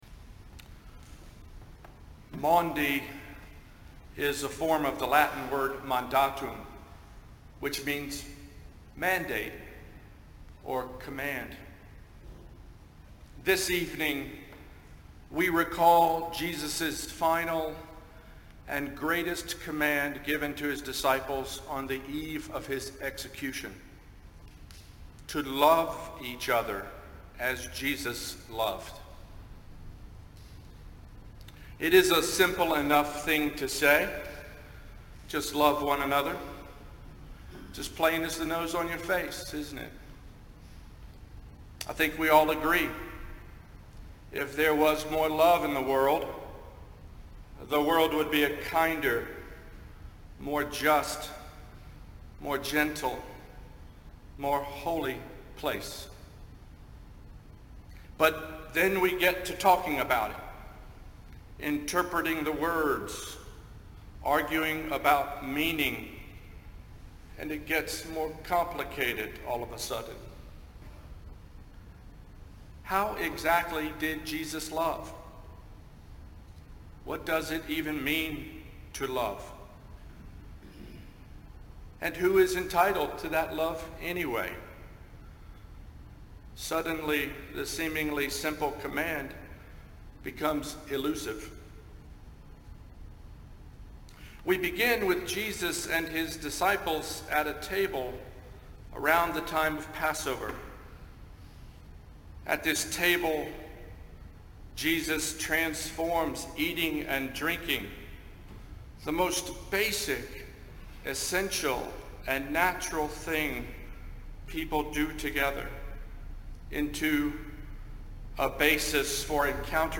preaches on Maundy Thursday.